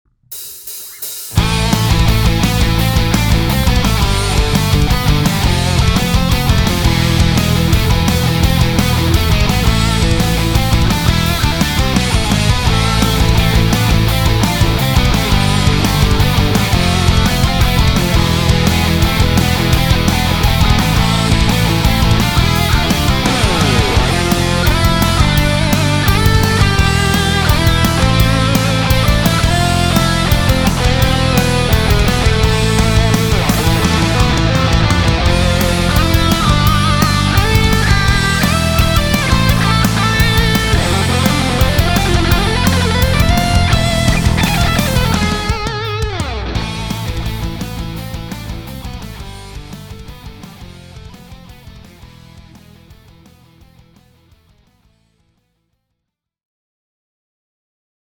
Style 2: Video Game Metal
Now, instead of playing those chords as full chords, we'll play them as powerchords to give it a heavier metal sound. We'll include palm mutes, and syncopated changes, as well as a pedal point melody. The lead guitar uses the A minor scale over the first three chords, then switches to A Harmonic Minor over the E chord at the end.
metal-andalusian.mp3